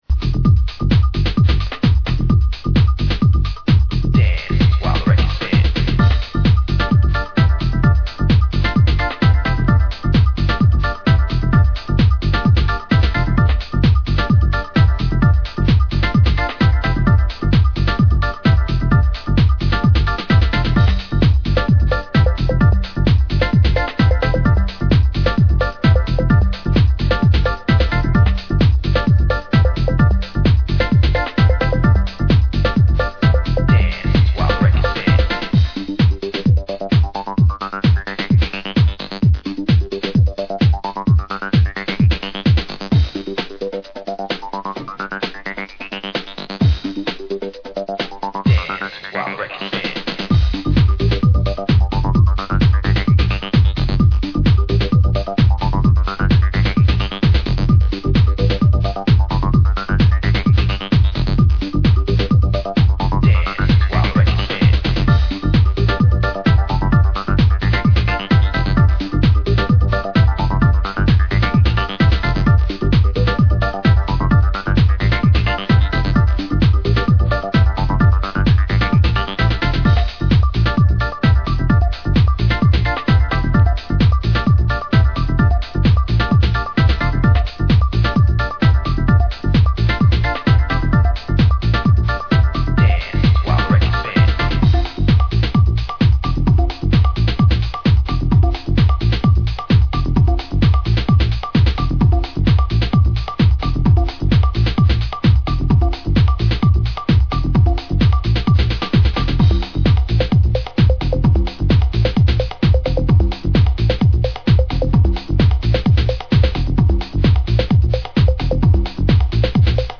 blends elements of 90s house, UK prog
lush warm production
" sets the tone with its 909 groove and cut vocal work.